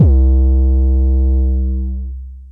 TM88 Heart808.wav